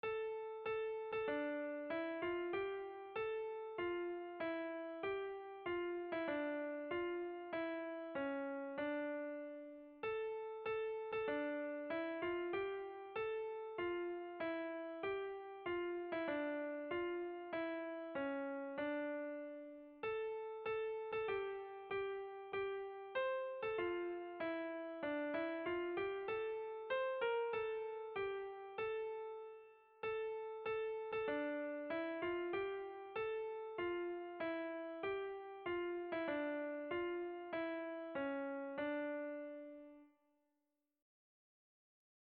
Irrizkoa
Zortziko handia (hg) / Lau puntuko handia (ip)
AABA